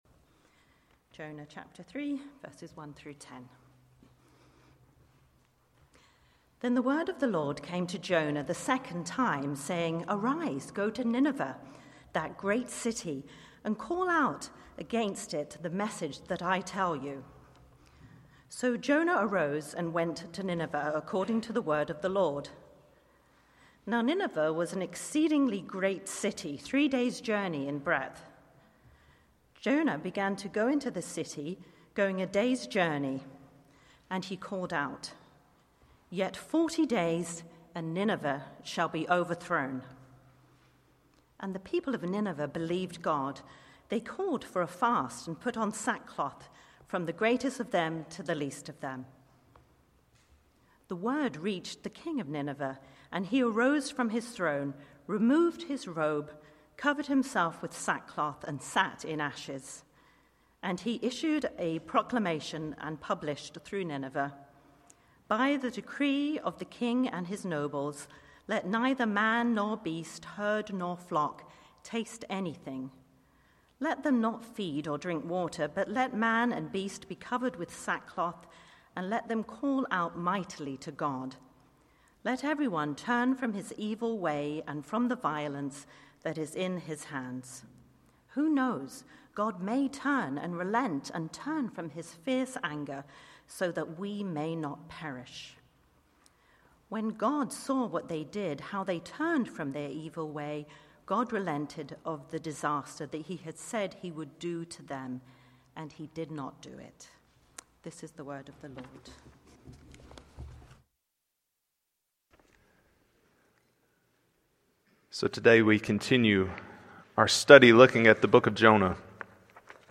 Passage: Jonah 3:1-10 Sermon